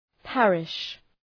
Προφορά
{‘pærıʃ}